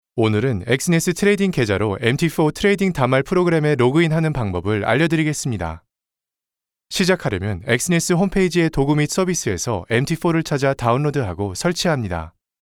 E-learning
Jovem adulto
Meia-idade
ConfiávelCrívelSimpático